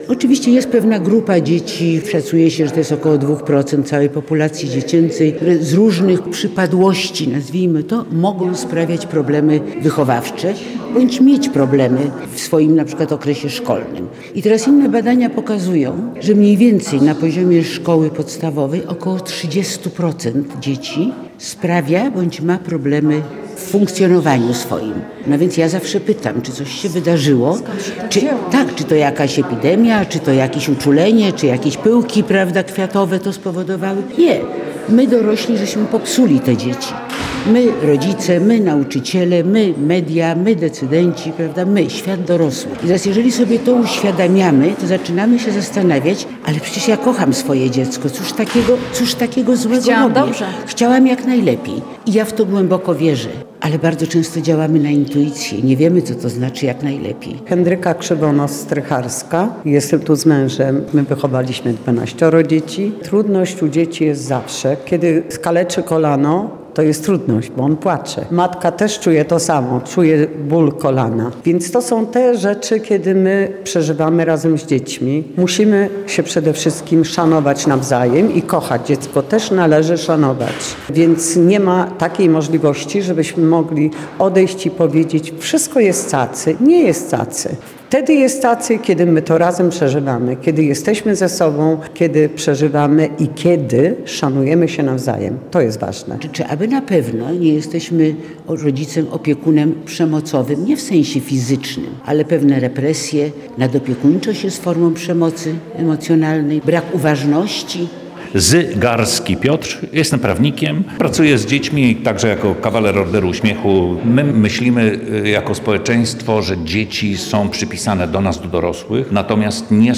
O tym jak i przed czym chronić dzieci i młodzież oraz jak tworzyć im bezpieczne środowisko, zarówno w szkole jak i w domu rozmawiano w czwartek (11.12) w Zamościu podczas konferencji zorganizowanej przez zamojską delegaturę Kuratorium Oświaty w Lublinie.